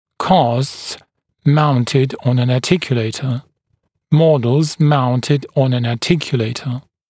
[kɑːsts (‘mɔdlz) ‘mauntɪd ɔn ən ɑːˈtɪkjuleɪtə]